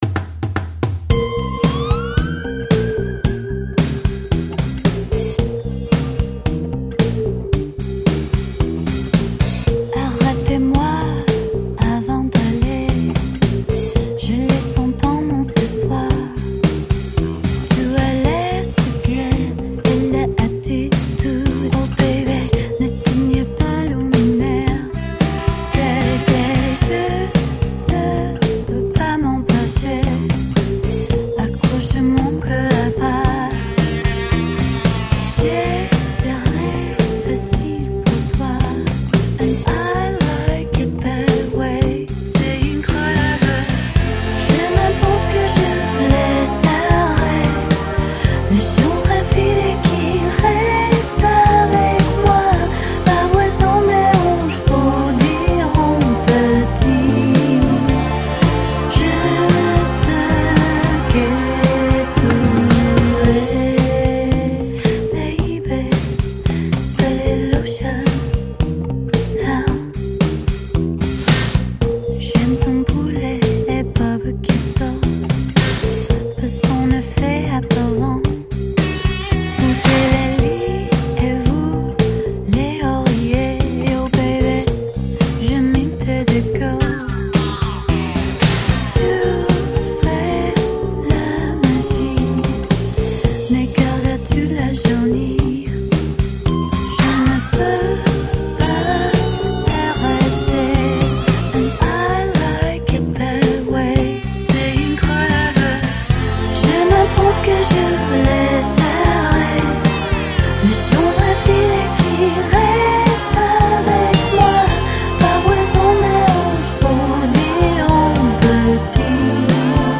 Жанр: Lounge, Downtempo, Nu Jazz, Pop